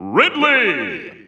The announcer saying Ridley's name in English and Japanese releases of Super Smash Bros. Ultimate.
Ridley_English_Announcer_SSBU.wav